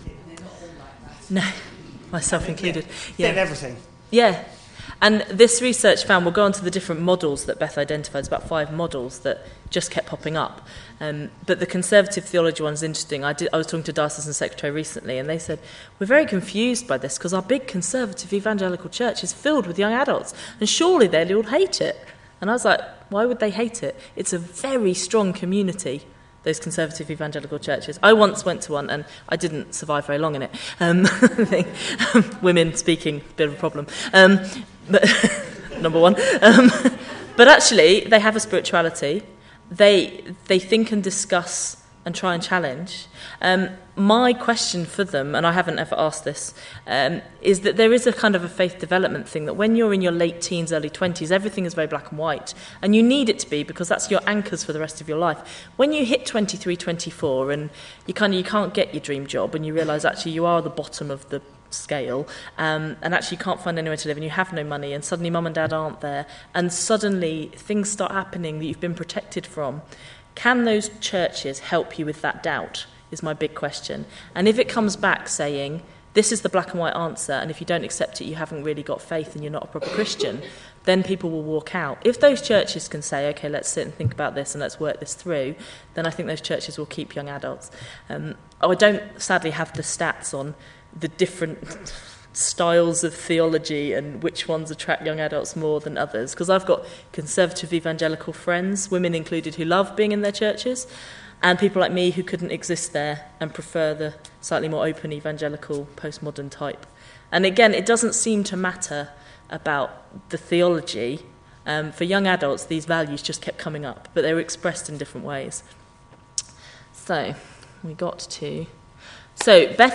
The second part of a session at the NDN conference